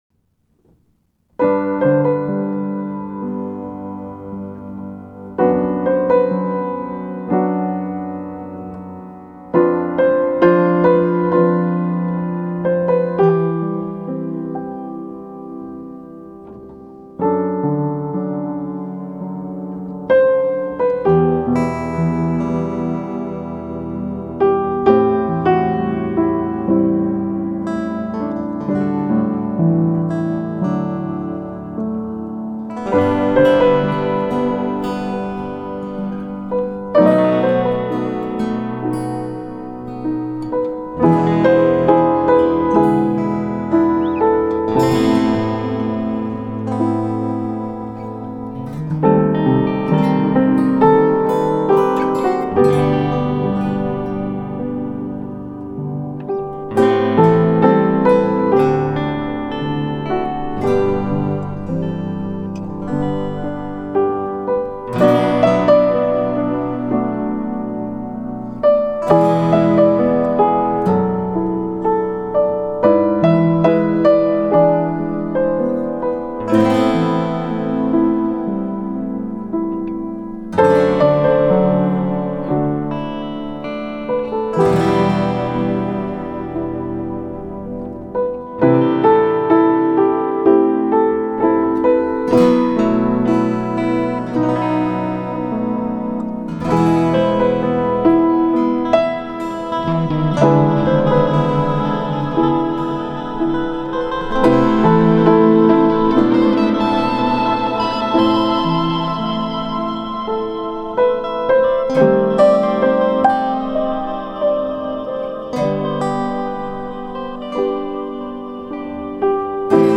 Gitarer, pålegg av stryk og mix
musikk komposisjon og piano